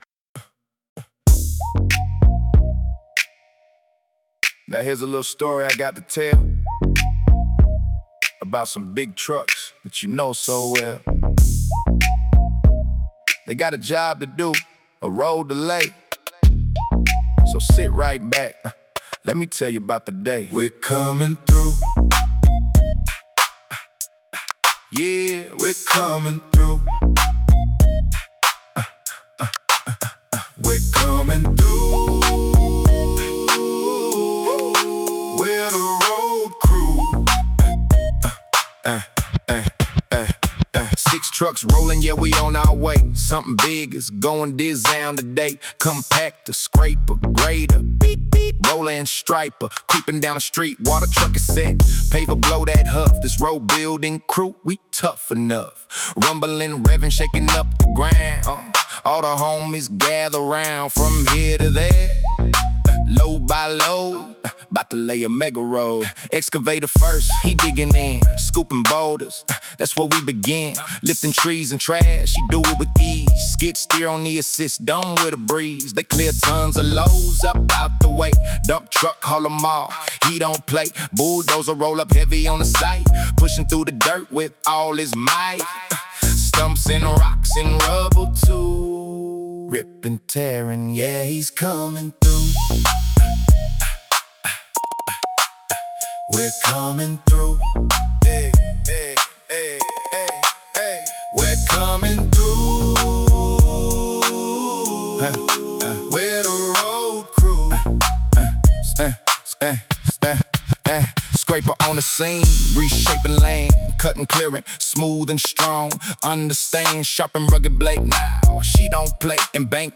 Early 2000s Hip Hop
Early 2000s hip hop meets bedtime.